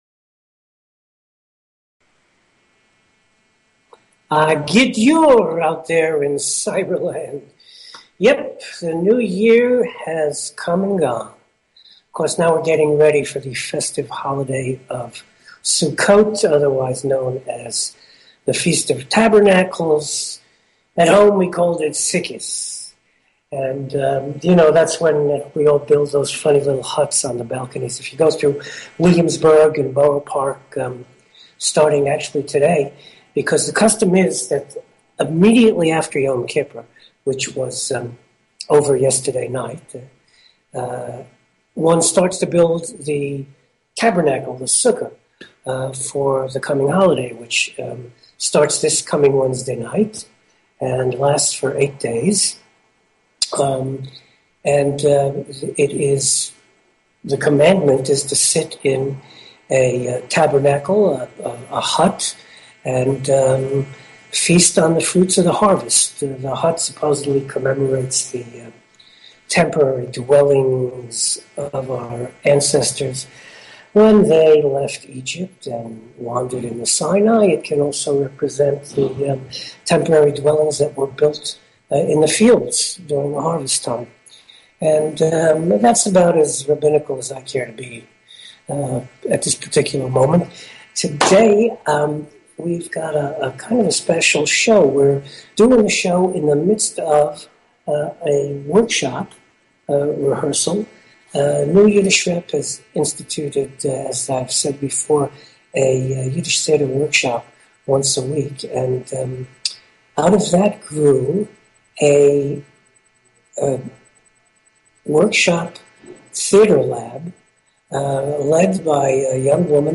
Talk Show Episode, Audio Podcast, New_Yiddish_Rep_Radio_Hour and Courtesy of BBS Radio on , show guests , about , categorized as
A forum for Yiddish Culture on internet radio. Talk radio in Yiddish, in English, sometimes a mix of both, always informative and entertaining.